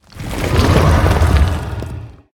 行为语音下载